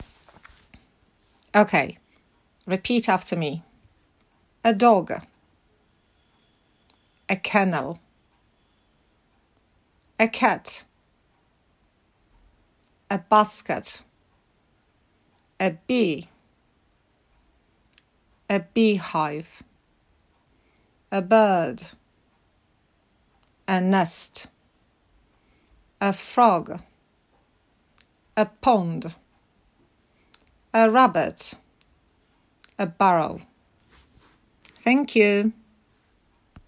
Teraz kliknij play▶ i powtórz ich nazwy po angielsku (czytam od lewej, piesek, do dolnego prawego rogu, królicza norka):